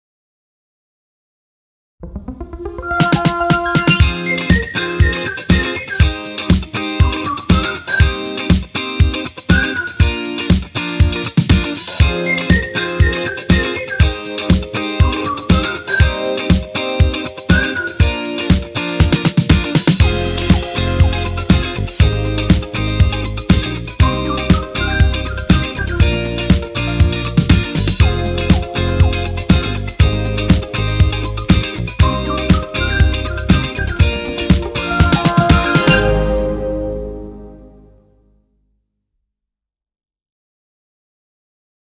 Identiteit & jingle